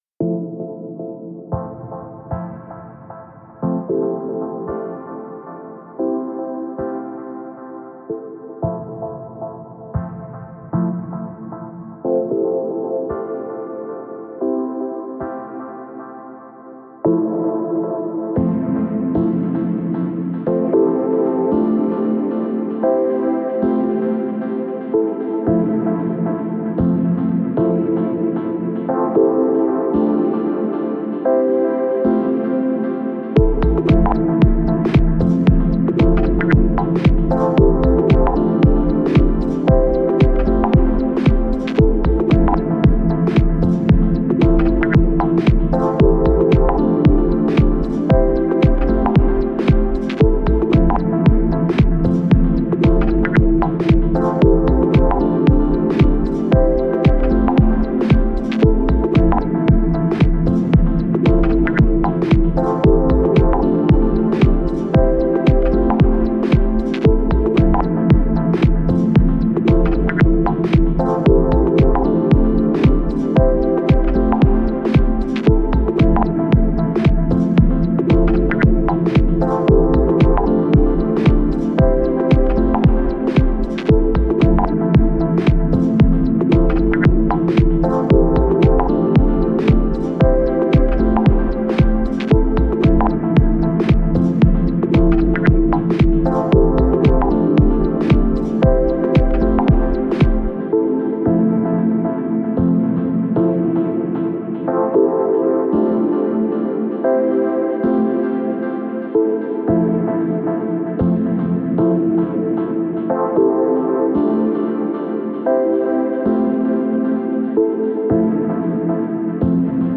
Genre: Ambient/Dub Techno/Deep Techno.